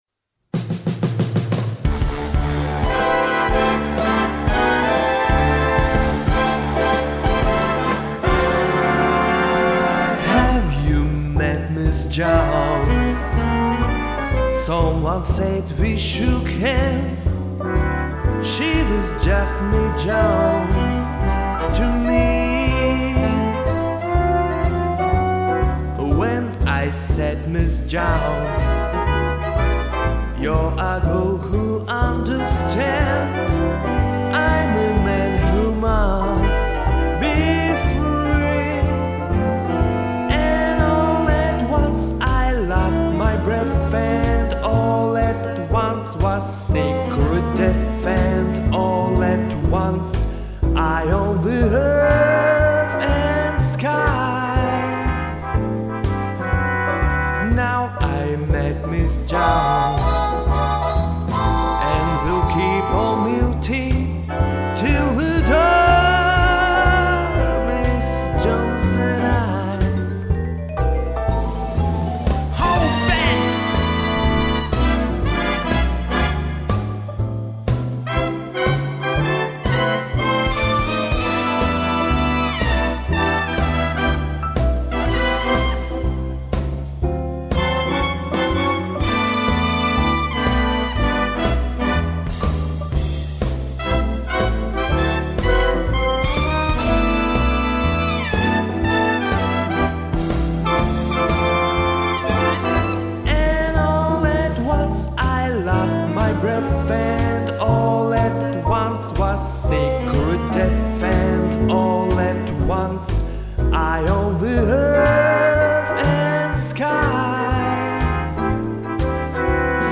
Комментарий инициатора: Что-нибудь из джаза.